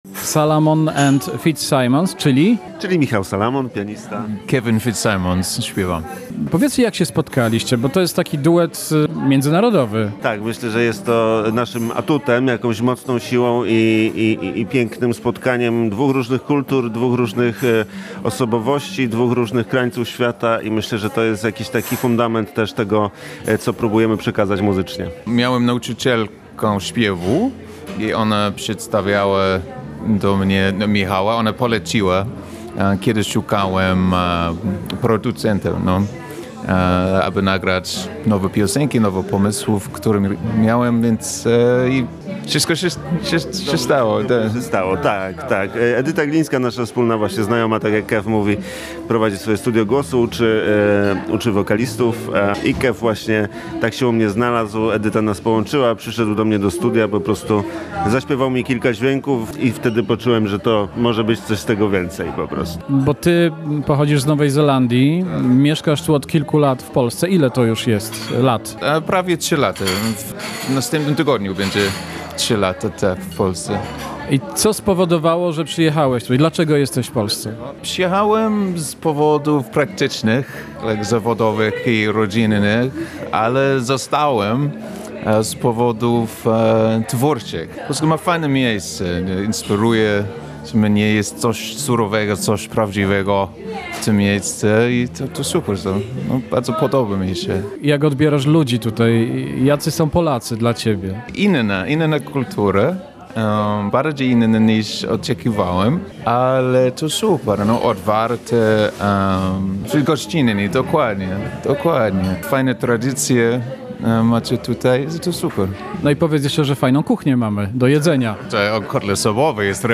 Czyli historia o tym, jak polski jazzman, pianista i kompozytor spotyka wokalistę i songwriter’a z Nowej Zelandii.
Ich wspólny projekt to fascynujące spotkanie dwóch muzycznych światów tworzących przestrzeń, w której klasyczne indie łączy się z jazzem, tworząc unikatową całość.
Dwa filary współpracy: baryton i fortepian To kierunek osadzony na dwóch solidnych filarach.